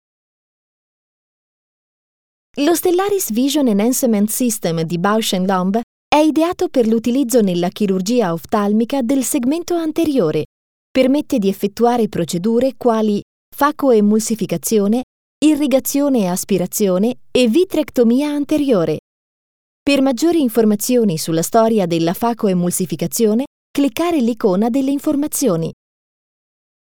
Professional home recording studio. My voice is friendly young and smiling. Smooth and professional for presentations.
Sprechprobe: Industrie (Muttersprache):
Italian professional female Voiceover Talent.